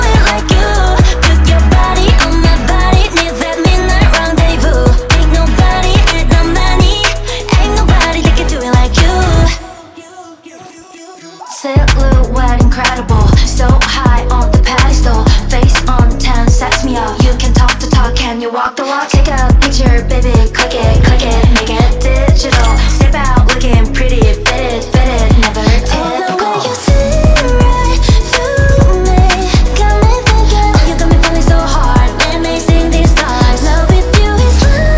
Жанр: Поп музыка
K-Pop, Pop